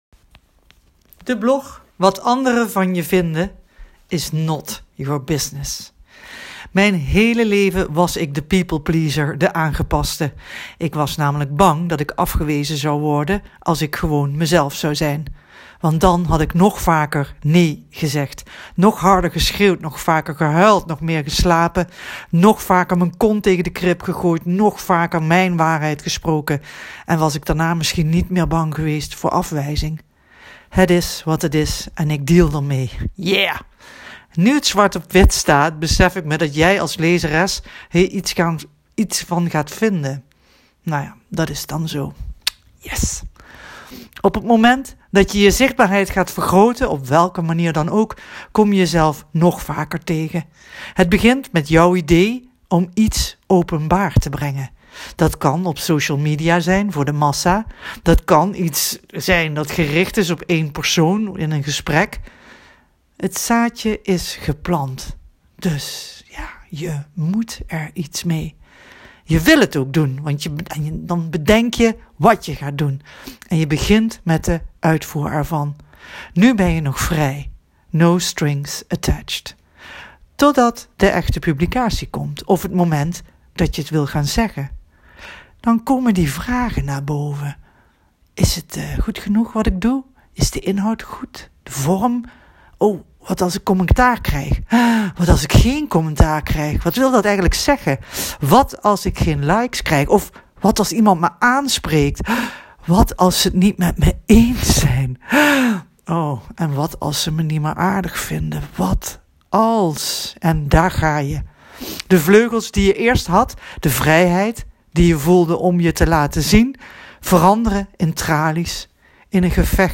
Deze keer heb ik de blog opgenomen.
Gewoon via de dictafoon. Daarin vertel ik je mijn voorbeeld van de 5G's, van die keer dat ik in de stoel van de ortho zat, en het niet ging zoals ik het wilde...